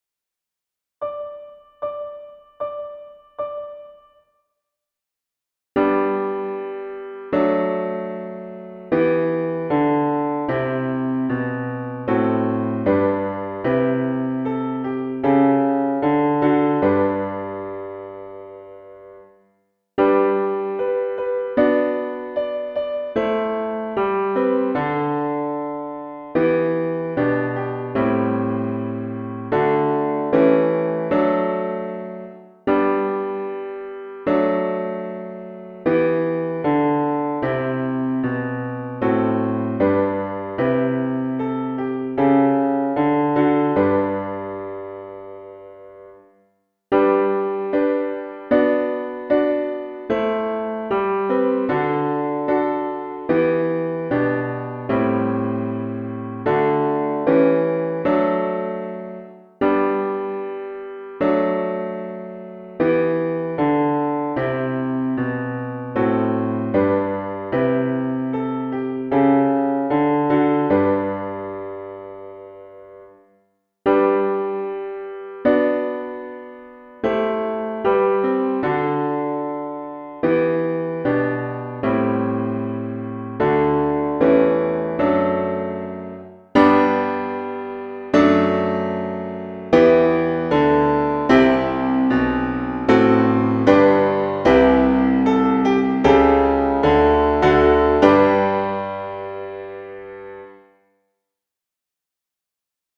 base Pianoforte